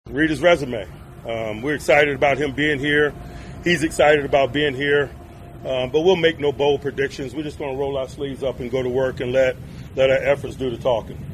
Coach Mike Tomlin set the tone for the minicamp, saying Rodgers is his starting quarterback, for obvious reasons.